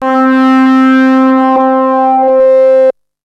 TALKING OSC 4.wav